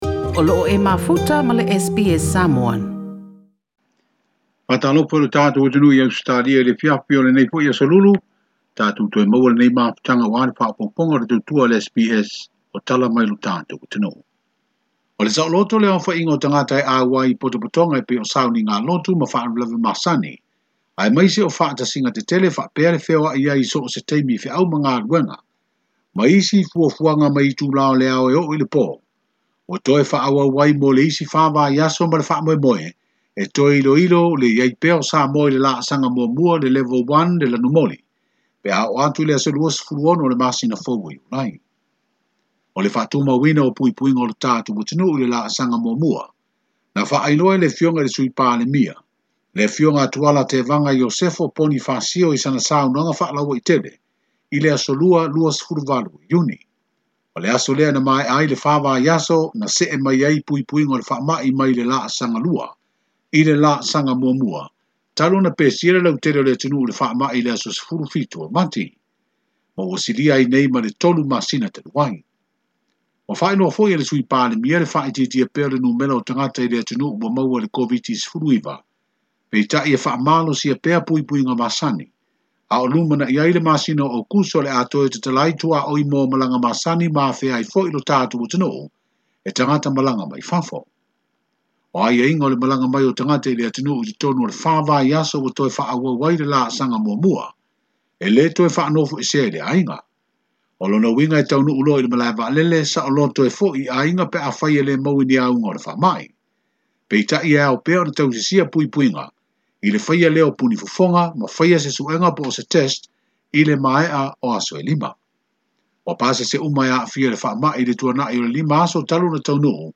Na ia ta'ua i se talanoaga ma le sui o le SBS Samoan